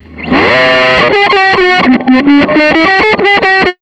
DJ130GUITR-L.wav